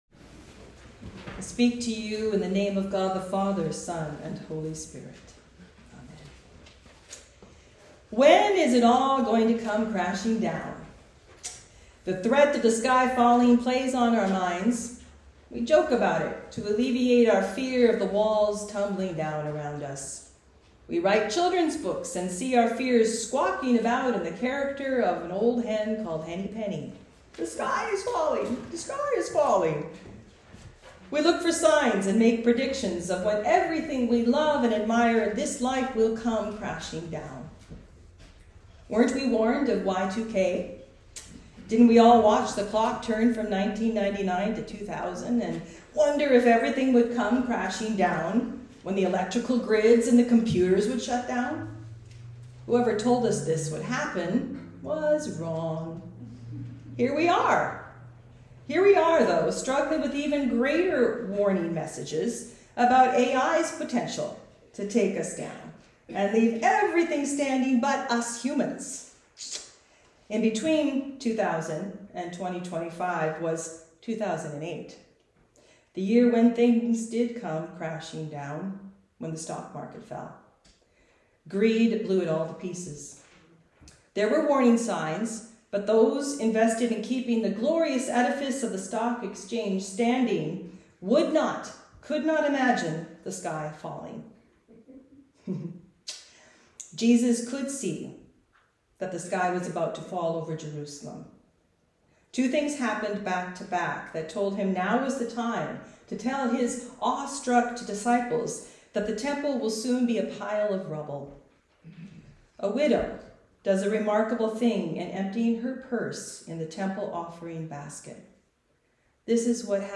Talk on Luke 21.5-19